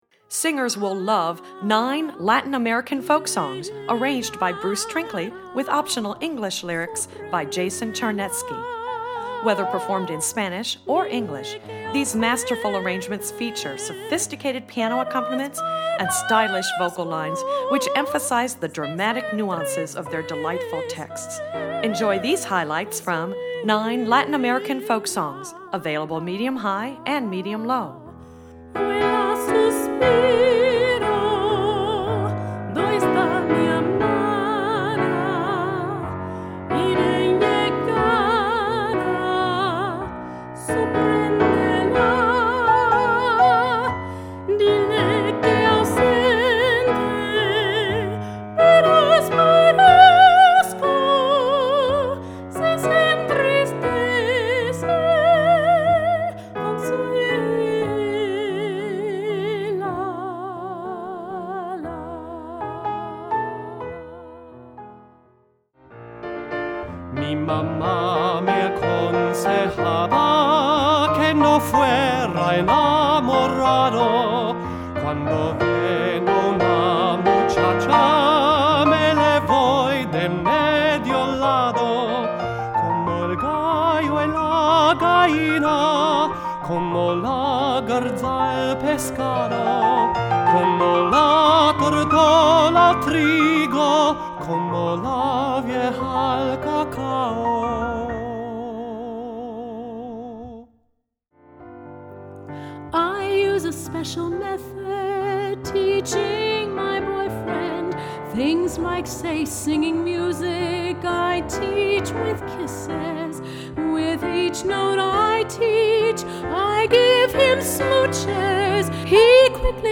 Voicing: Medium-High Voice